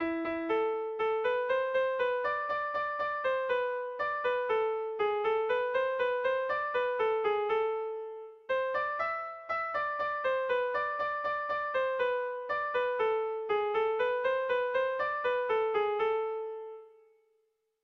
Erlijiozkoa
Zortziko txikia (hg) / Lau puntuko txikia (ip)
ABDB